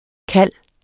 Udtale [ ˈkalˀ ]